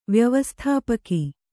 ♪ vyavasthāpaki